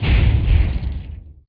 boom06.mp3